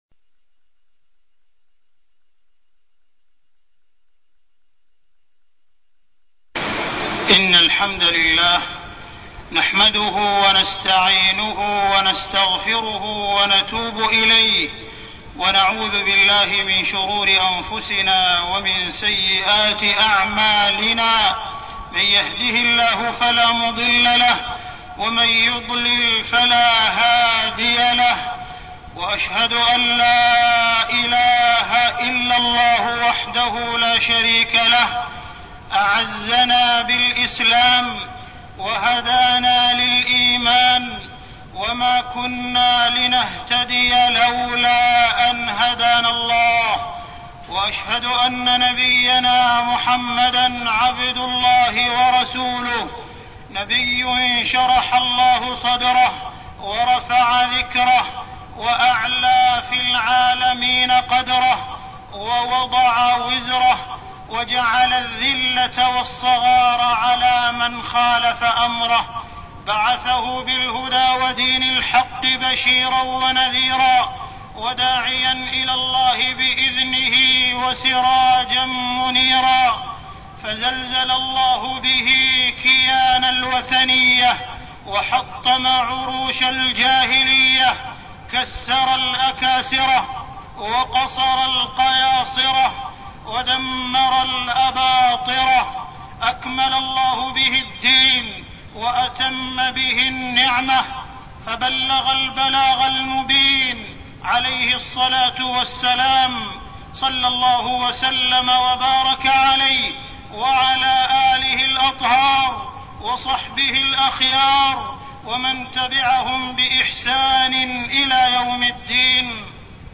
تاريخ النشر ٣ ذو القعدة ١٤١٦ هـ المكان: المسجد الحرام الشيخ: معالي الشيخ أ.د. عبدالرحمن بن عبدالعزيز السديس معالي الشيخ أ.د. عبدالرحمن بن عبدالعزيز السديس التمسك بتعاليم الدين